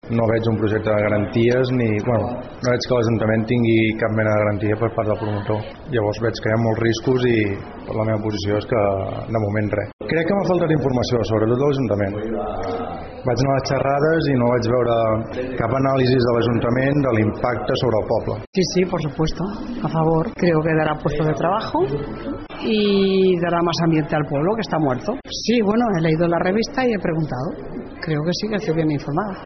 Dels que s’han atrevit, hi ha veus a favor i en contra.